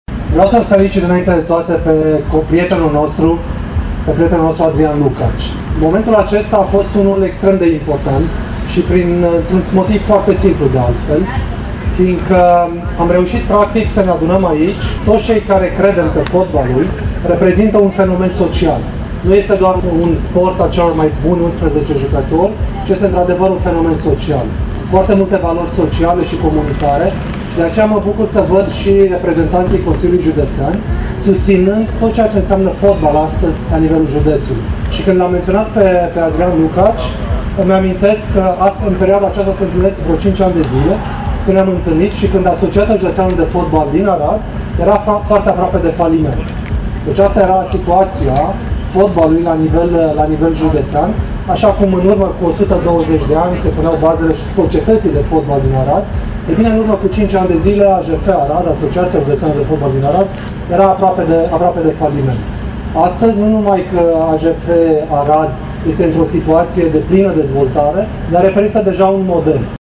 Președintele FRF, Răzvan Burleanu, a remarcat meritele celui care a fost sufletul sărbătorii